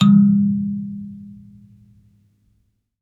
kalimba_bass-G#2-mf.wav